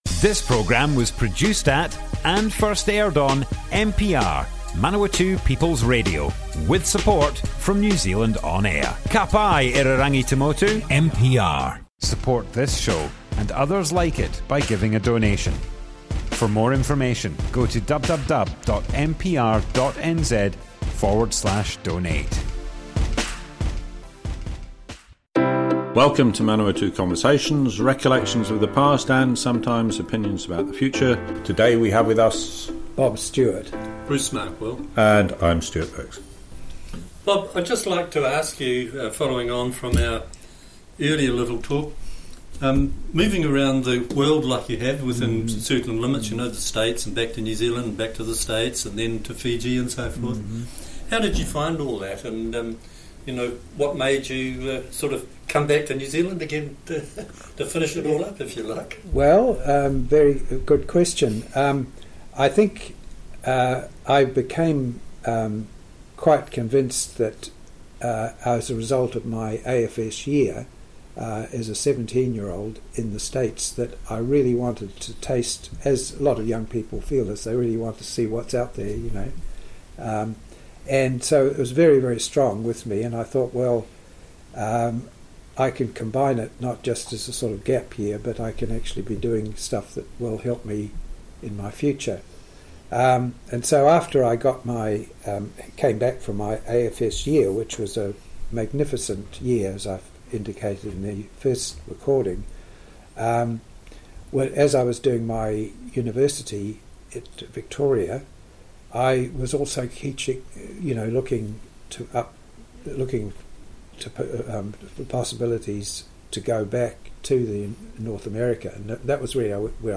Manawatu Conversations More Info → Description Broadcast on Manawatu People's Radio 19th April 2022, part 2 of 4.
oral history